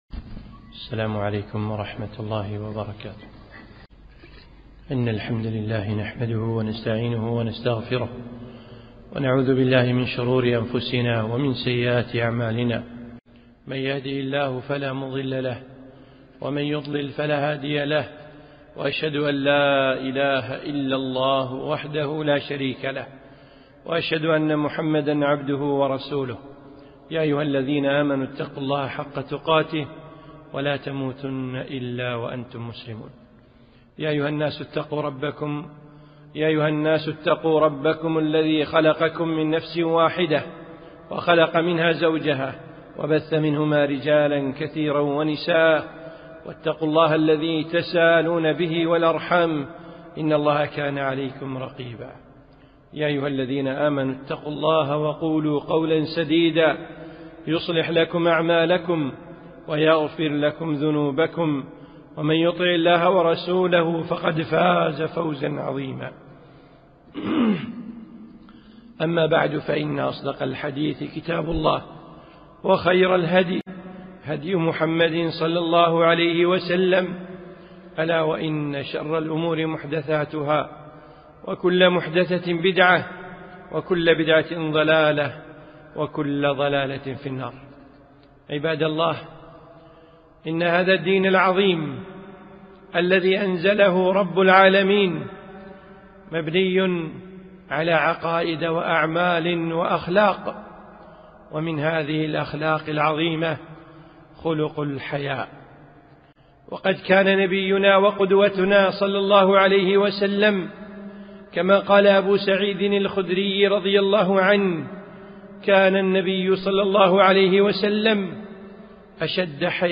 خطبة - زينة الحياء